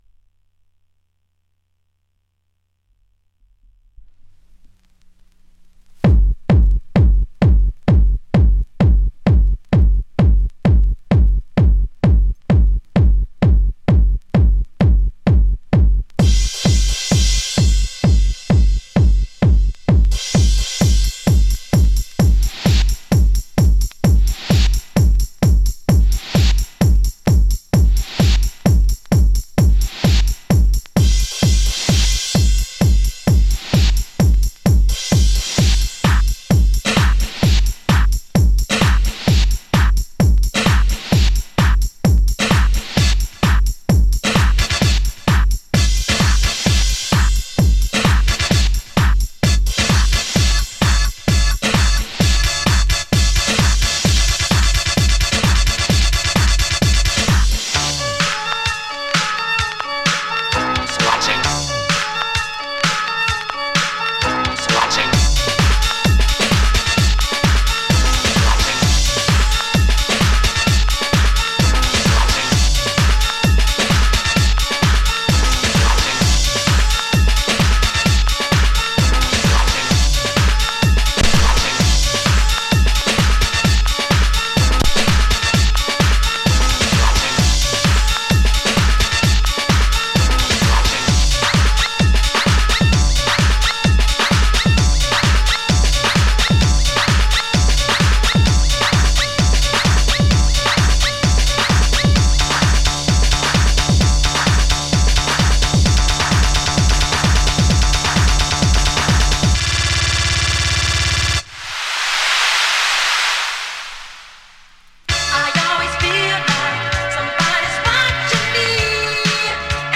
Deep House Remix